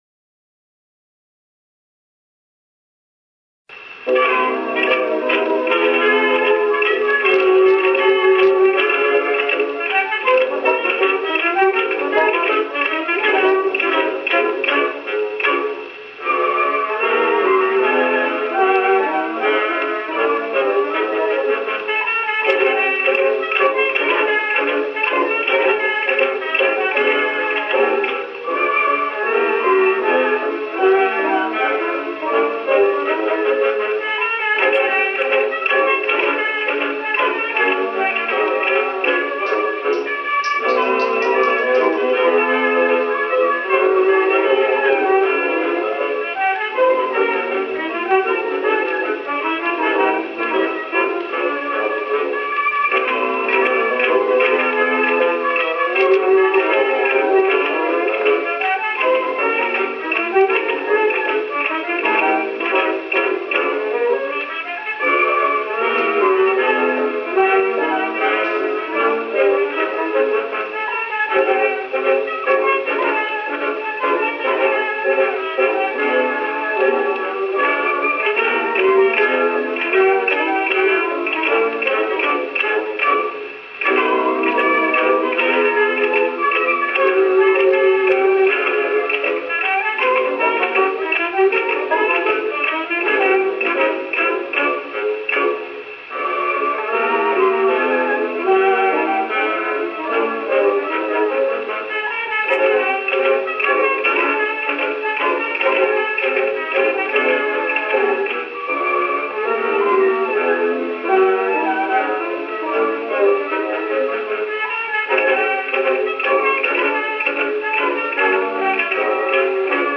Conductor and Violinist